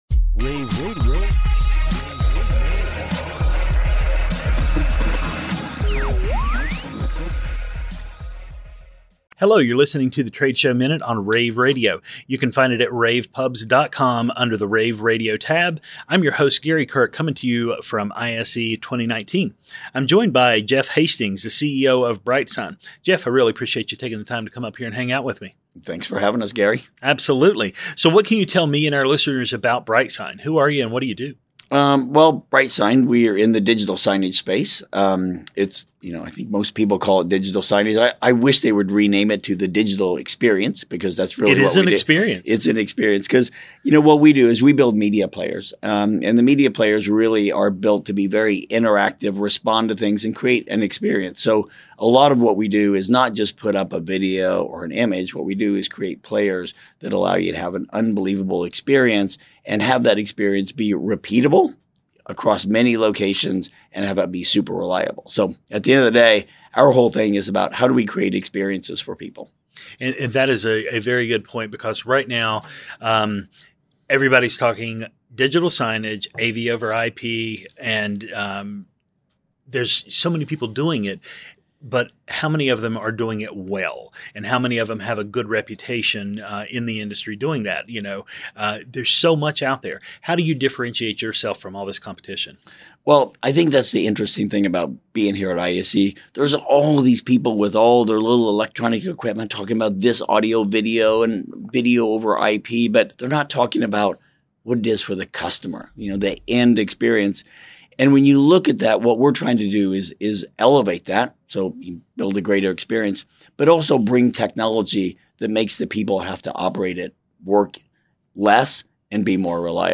February 7, 2019 - ISE, ISE Radio, Radio, rAVe [PUBS], The Trade Show Minute,